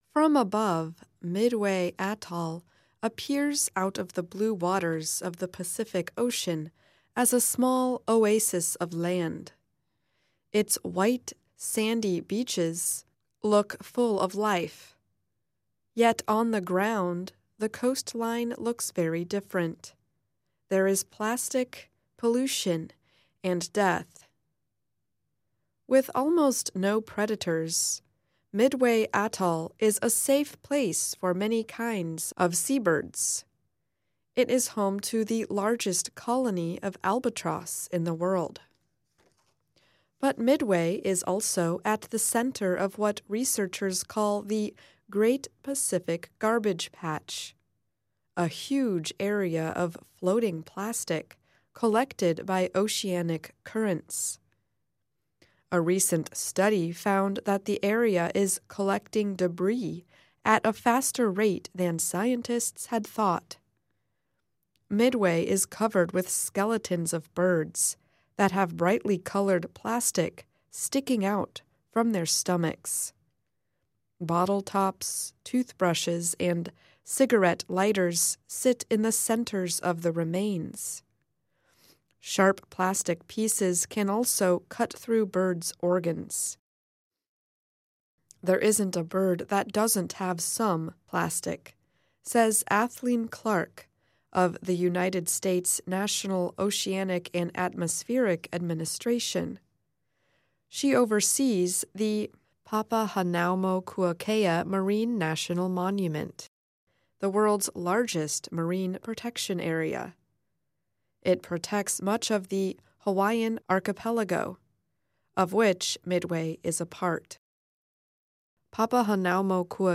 慢速英语:中途岛环礁覆盖着塑料与死鸟|慢速英语|慢速英语听力下载